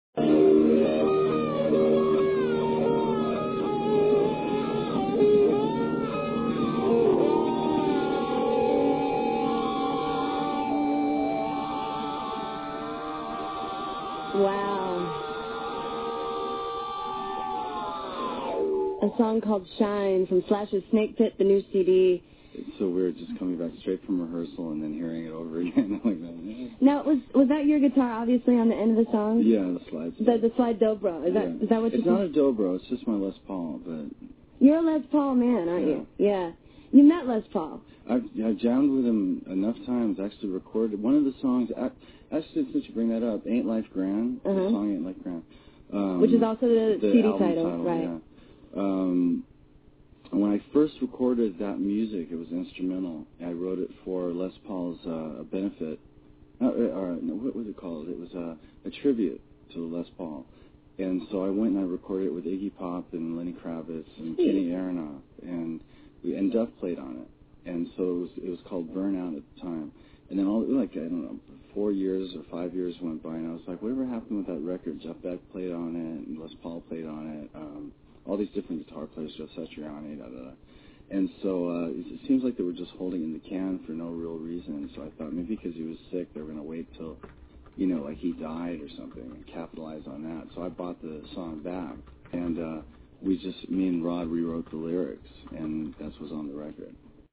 Soundbreak Interviews SLASH 7/26/00